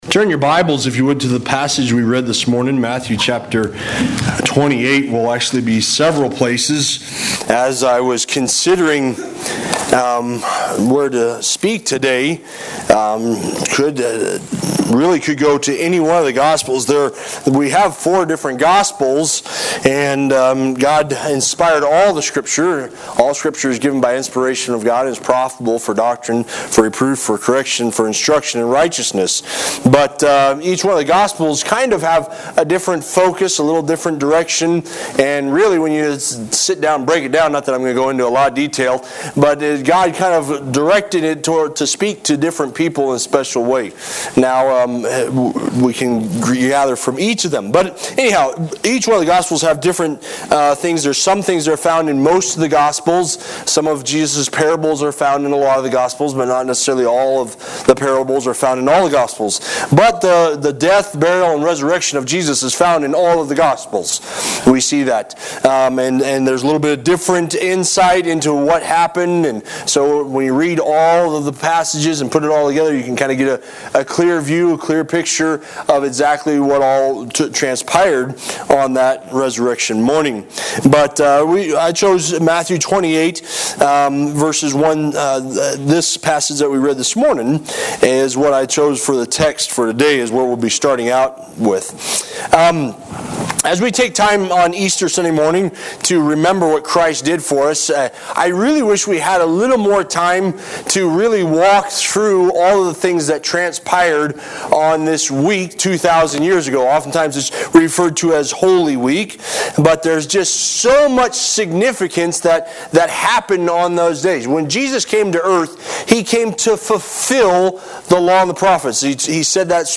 Bible Baptist Church, Sermon Archive for April, 2026